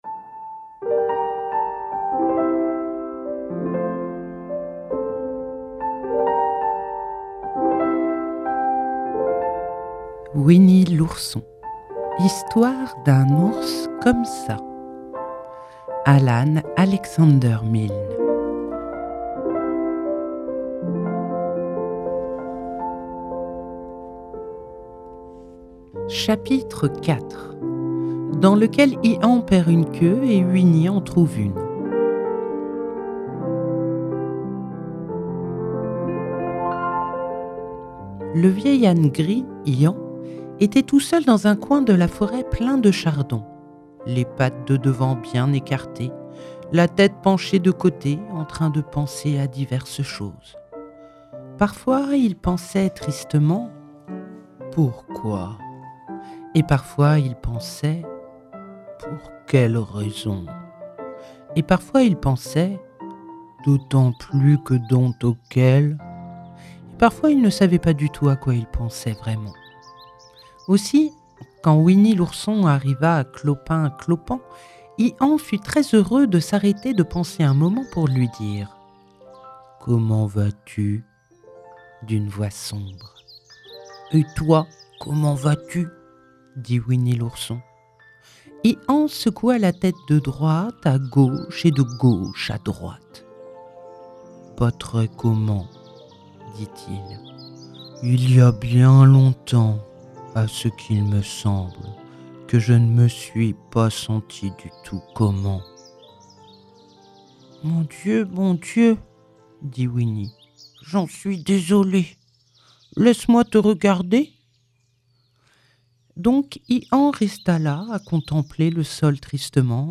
🎧 Winnie l’Ourson – Alan Alexander Milne - Radiobook